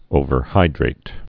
(ōvər-hīdrāt)